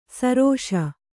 ♪ sarōṣa